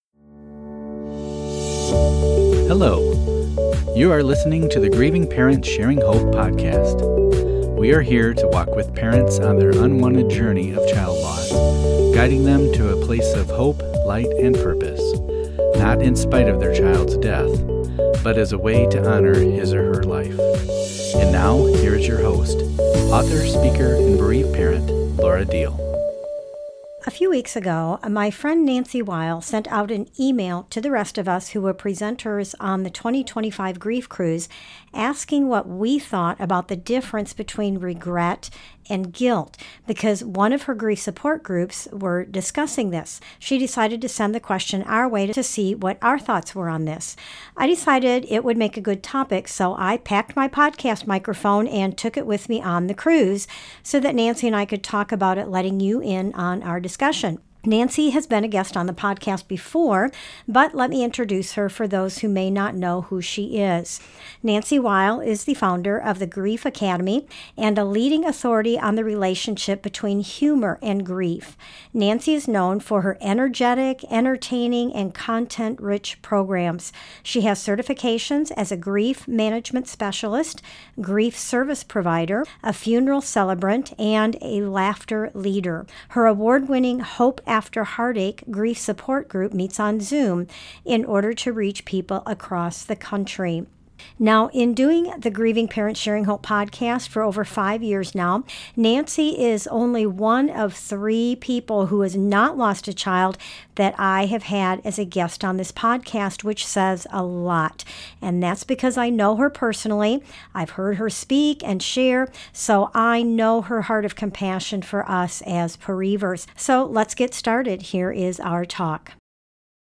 while aboard the 2025 Grief Cruise